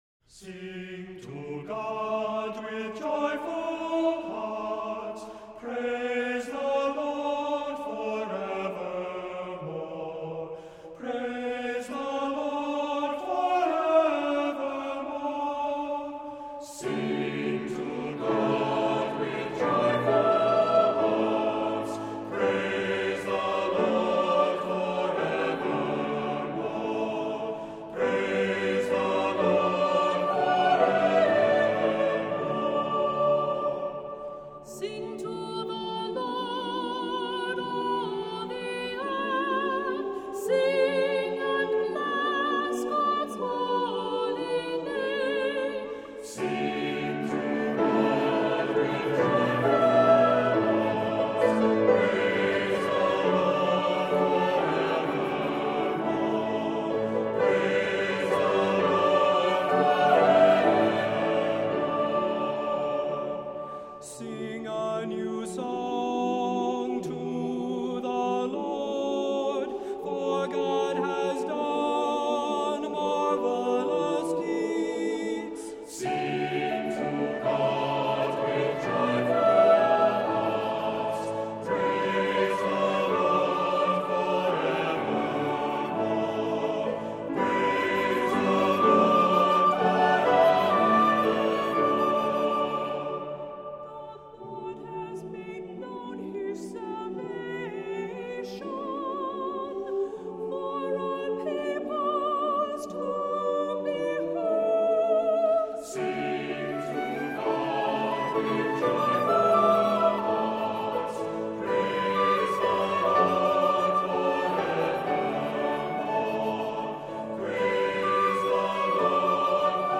Voicing: SATB; Cantor